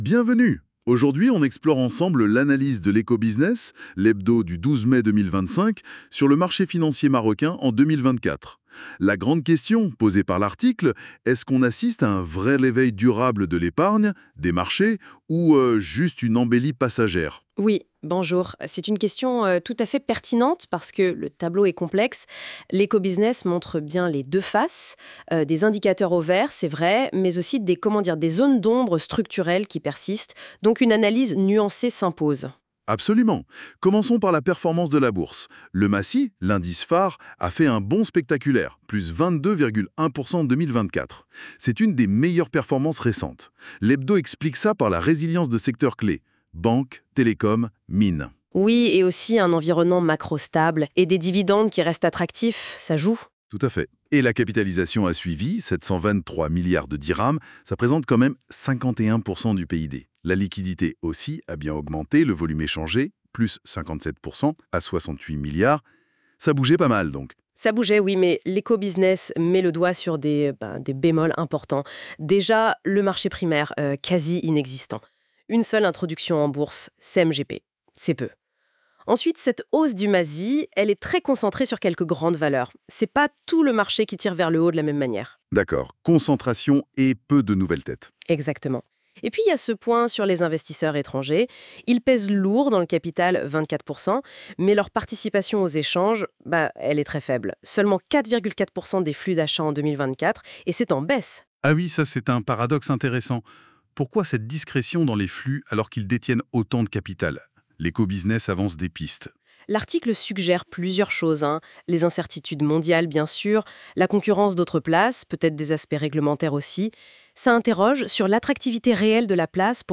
Débat (18.28 Mo) 1. Quel a été le comportement du MASI en 2024 et qu'est-ce qui a soutenu cette performance ? 2. La hausse du MASI en 2024 est-elle le signe d'un redressement durable ou d'un simple sursaut conjoncturel ? 3. Quel est le profil des investisseurs en Bourse au Maroc et y a-t-il des signes de changement ? 4.